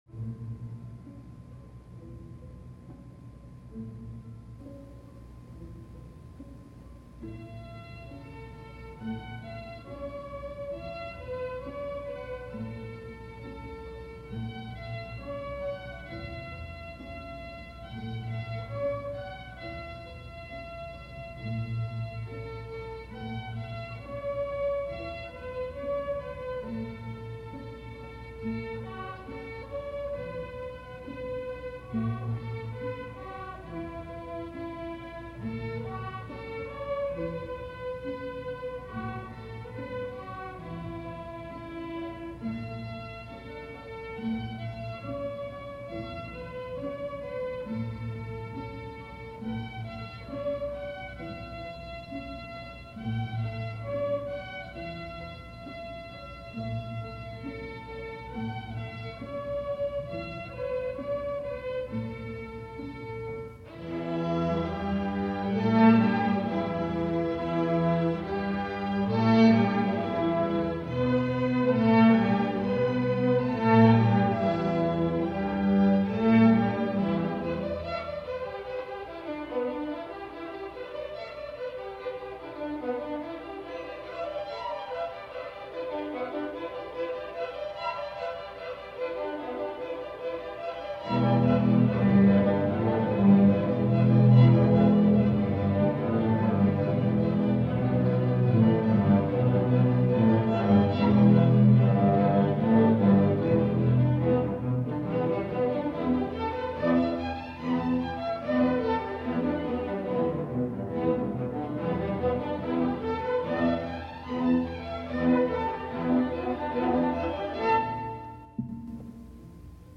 Deux mélodies folkloriques chinoises
sont présentées dans un contexte harmonique pentatonique.